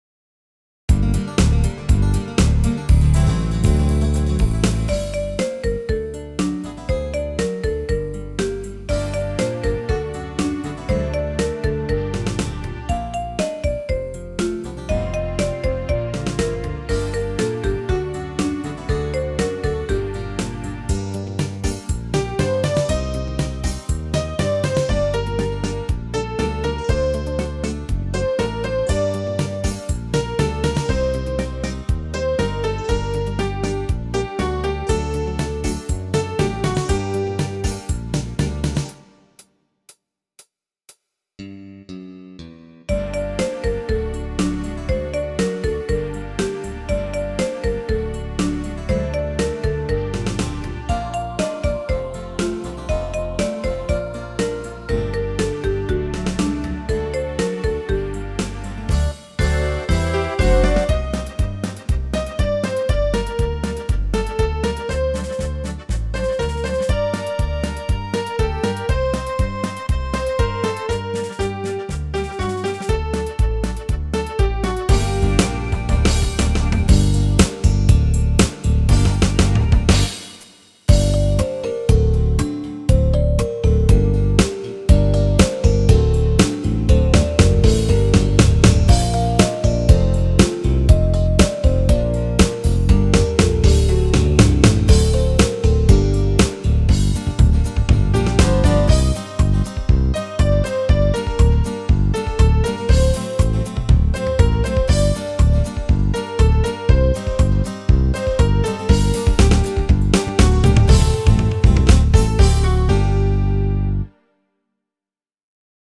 Перший сніг дитяча пісня
Світло-настроєво! heart Гарна вийшла пісня, Новим роком повіяло! ny4 Перший сніг - це завжди радість!